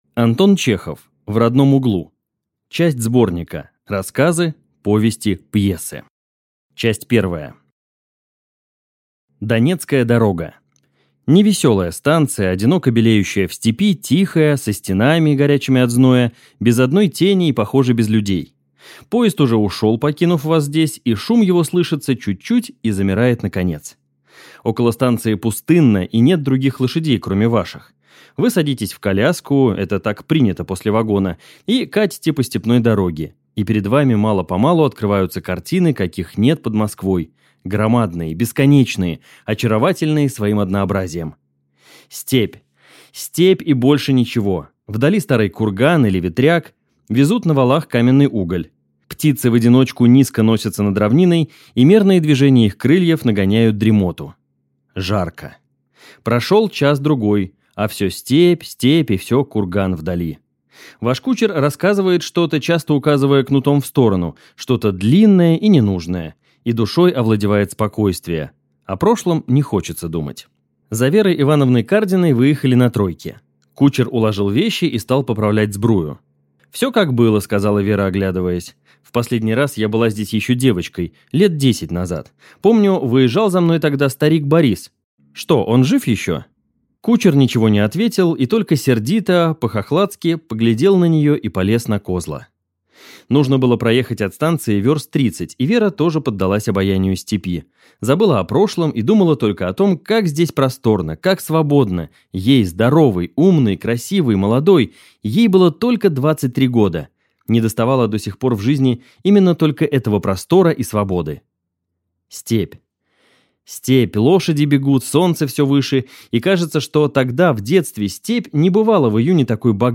Aудиокнига В родном углу
Читает аудиокнигу